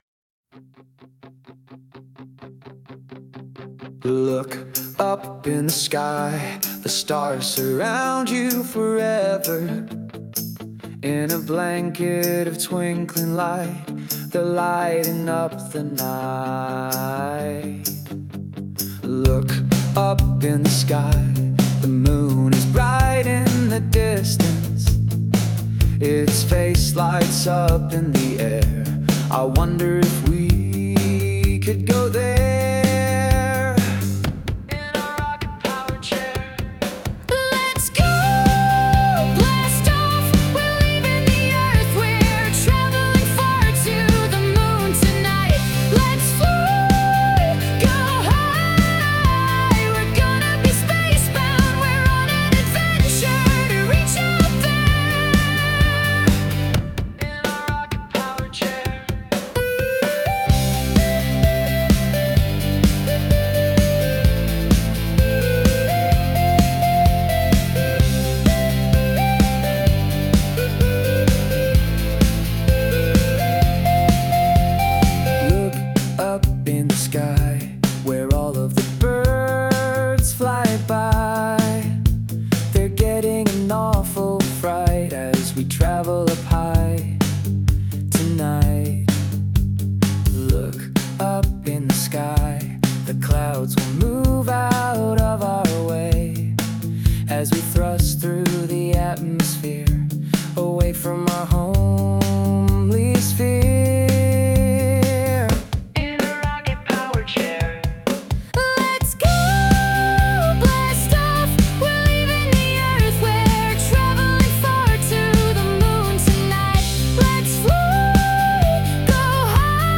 Sung by Suno
Look_up_in_the_sky_(Remix)_mp3.mp3